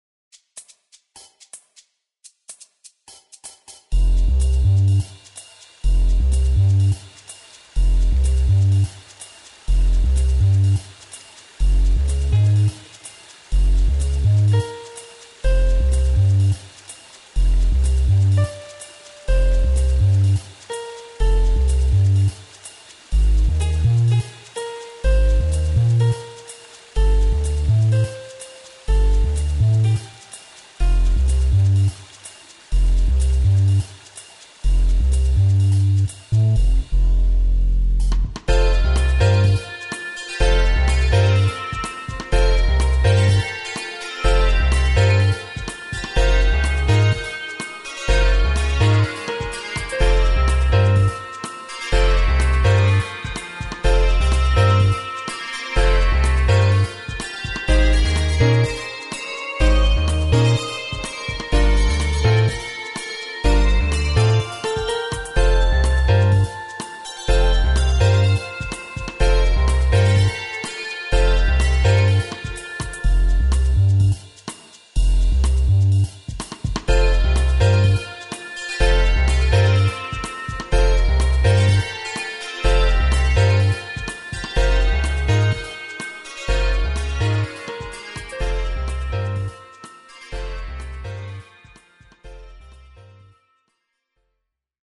Relaxációs cd 50 perc zenével.